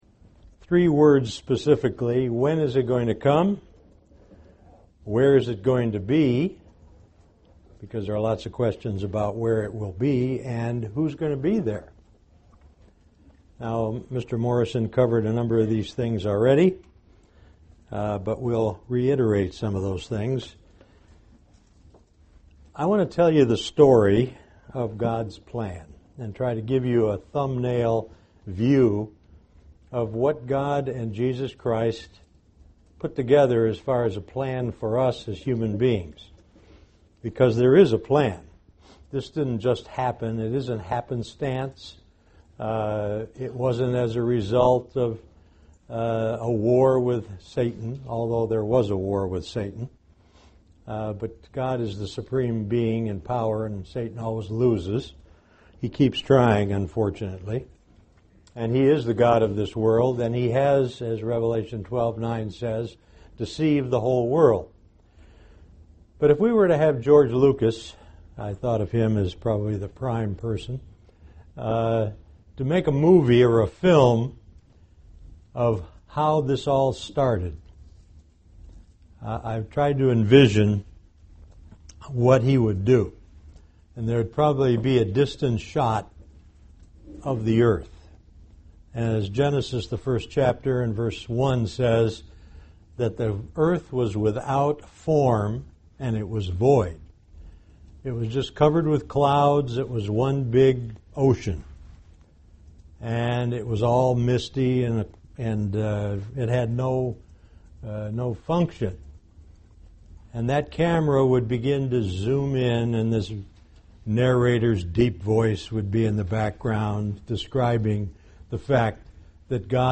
The Kingdom of God: When will it come? Where will it be? Who will be there? This message was given for a Kingdom of God seminar.
Given in Beloit, WI
UCG Sermon Studying the bible?